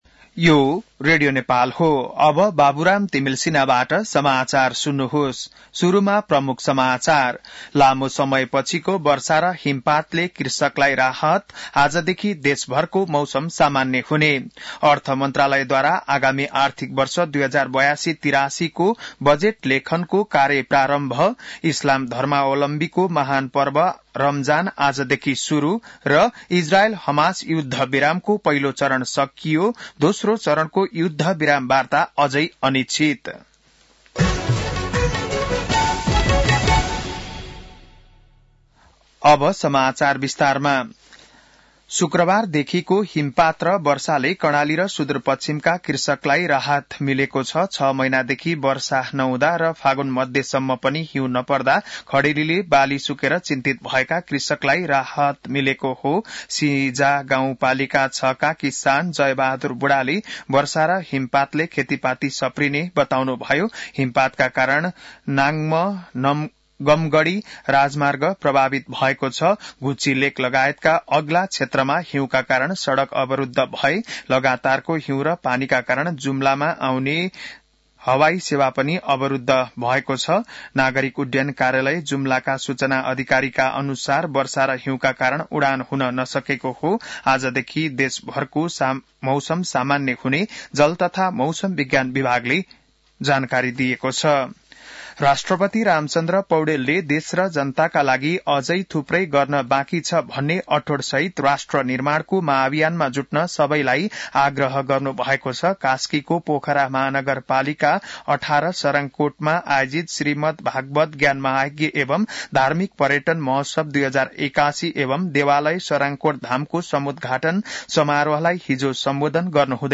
बिहान ९ बजेको नेपाली समाचार : १९ फागुन , २०८१